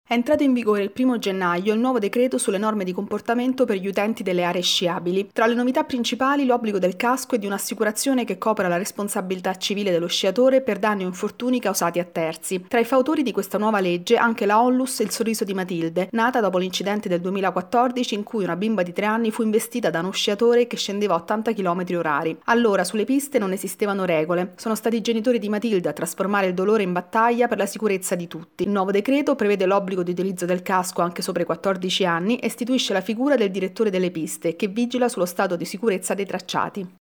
La lunga rincorsa. Nello sport italiano tante campionesse ma poche manager. Il servizio